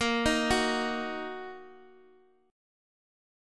Bbsus4#5 Chord
Listen to Bbsus4#5 strummed